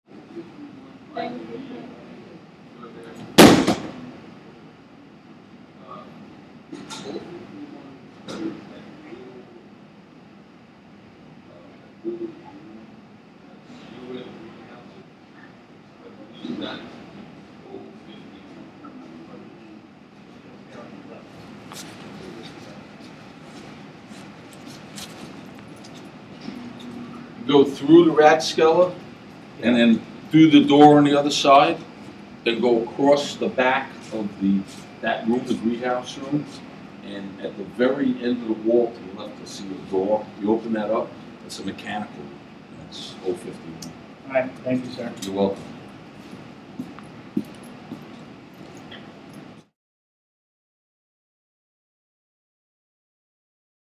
door close – Hofstra Drama 20 – Sound for the Theatre
Stairway of Republic Hall I recorded myself opening the door at the bottom of the stairs, going up, grabbing something at the top, returning down the steps and through the door once again. The door has a delay shutting, so you don’t hear the distinctive click until my footsteps on the stairs have already begun. You can hear how much heavier the footsteps are trudging up the stairs vs. hopping down them, as well as the difference in pattern.